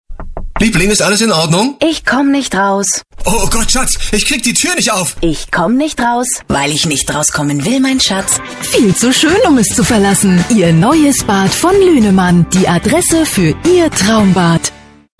O-Ton 1, Cramon, 11 sec.